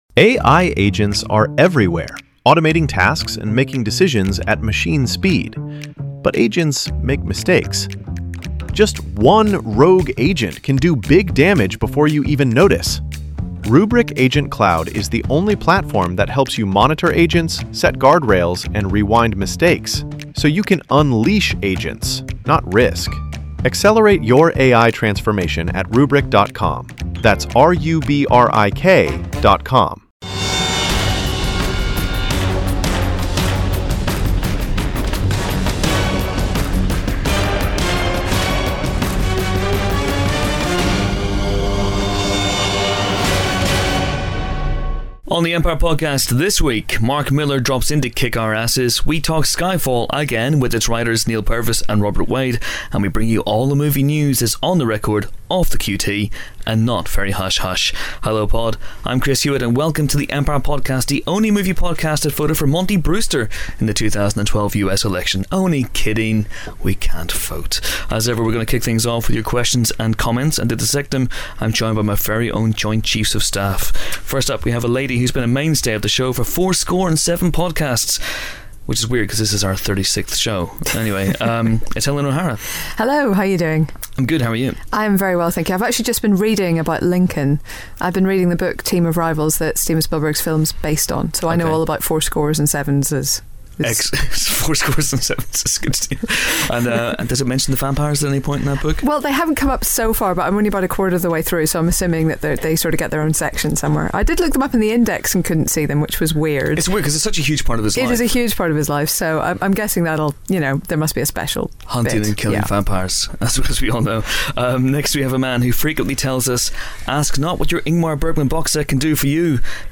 Appearing on this week's Empire Podcast are Kick-Ass and Wanted creator Mark Millar - and now head honcho of Fox's Marvel properties - and Skyfall writers Neal Purvis and Robert Wade. As well as those two interviews, there are discussions of actors that will put you off watching a movie entirely and the recent arrival of the trailer for World War Z. For all that and a whole lot more, click play or download or just subscribe to our RSS feed...